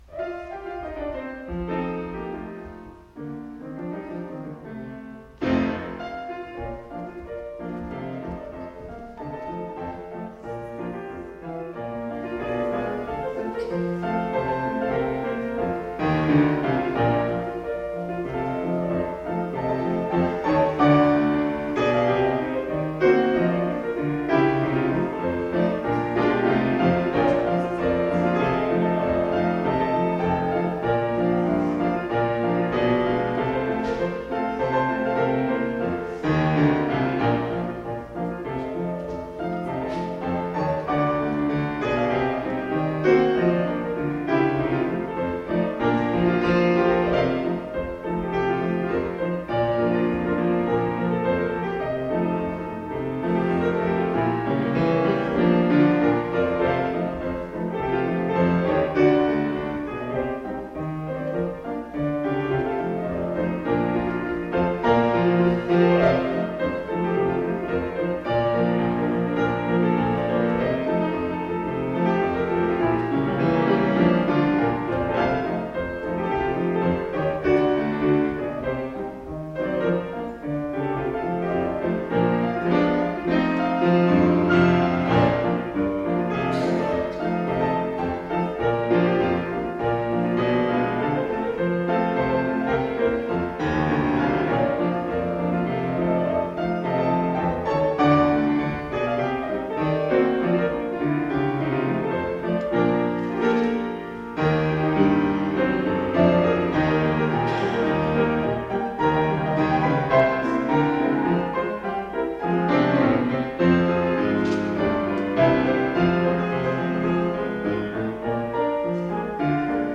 POSTLUDE -  Ole Virginny Barbecue  - J. E. Andino